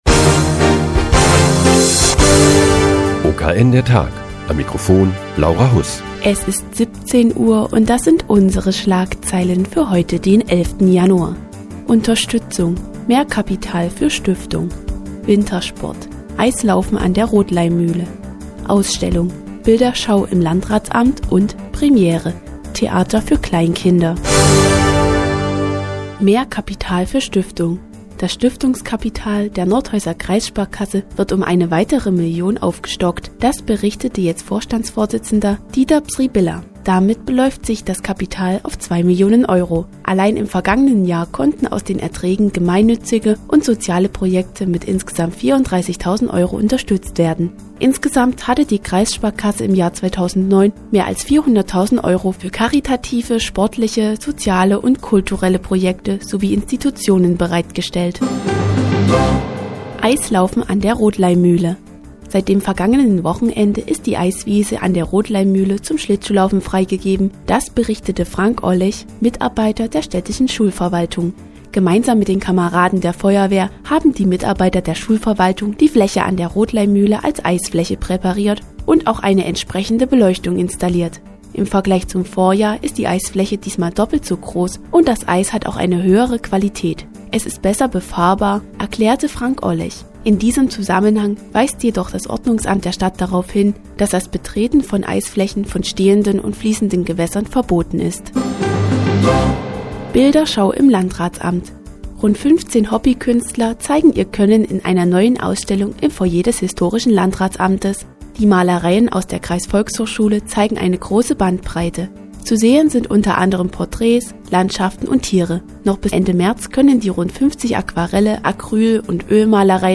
Die tägliche Nachrichtensendung des OKN ist nun auch in der nnz zu hören. Heute geht es um Eislaufen an der Rothleimmühle und eine Bilderschau im Landratsamt.